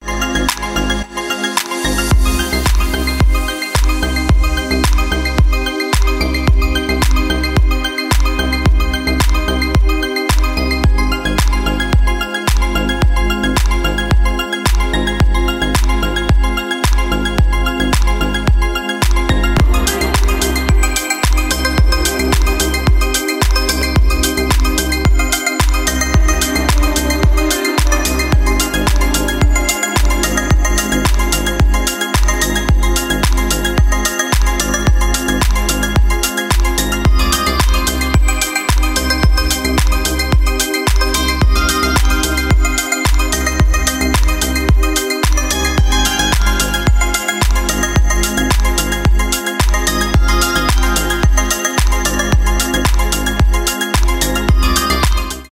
без слов , танцевальные
deep house , мелодичные
спокойные